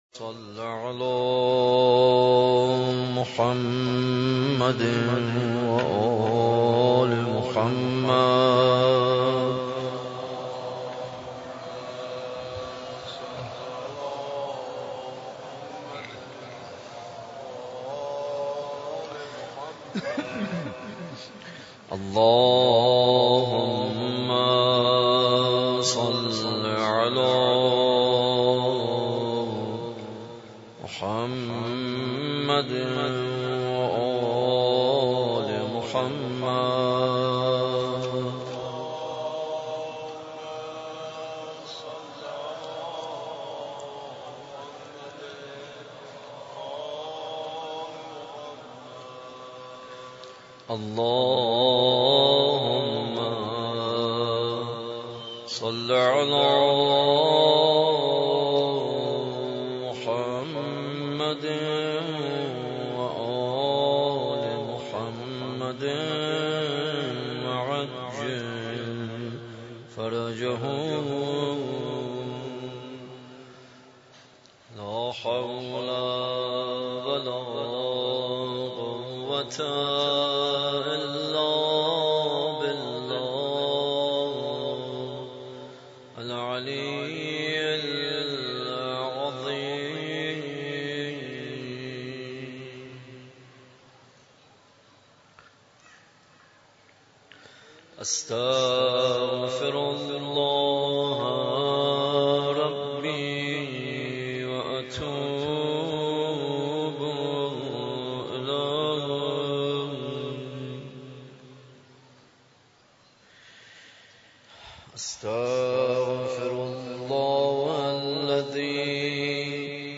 در مسجد امام حسین (ع) واقع در میدان امام حسین(ع) برگزار گردید.
دعای ابوحمزه